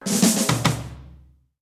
British SKA REGGAE FILL - 15.wav